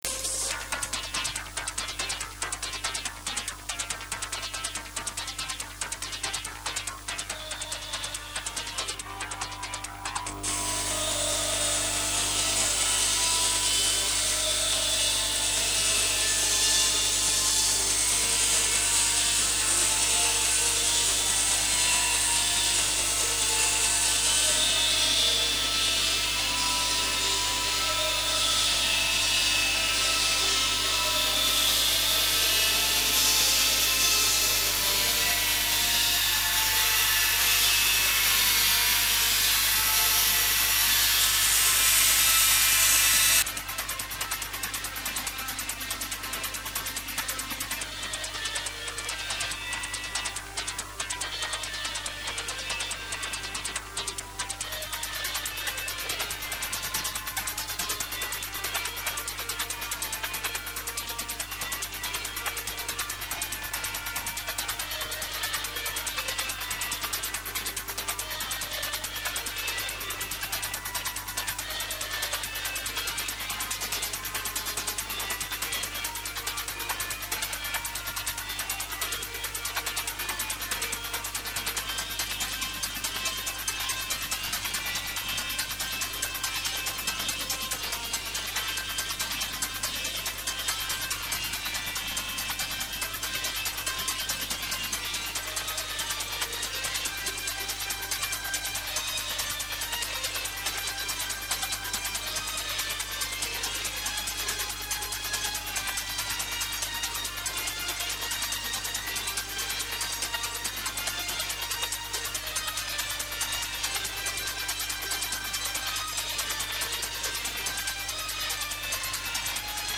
домашней студии